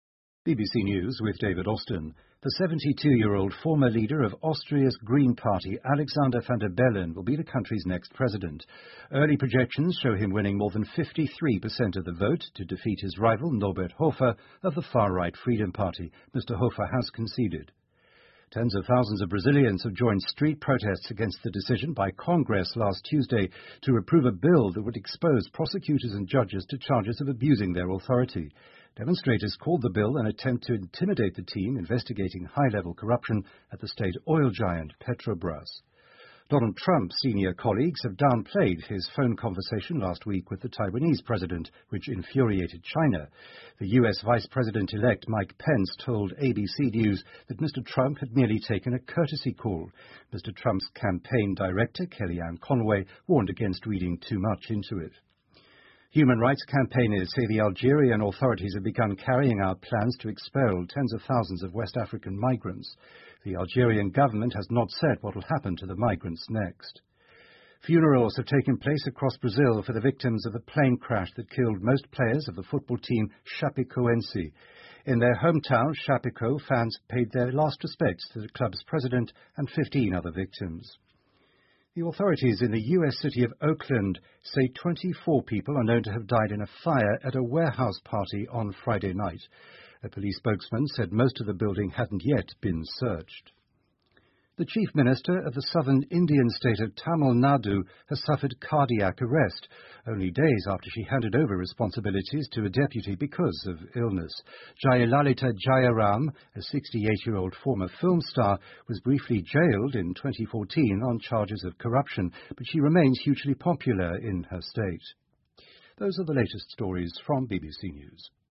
英国新闻听力 巴西为飞机失事遇难者举行葬礼 听力文件下载—在线英语听力室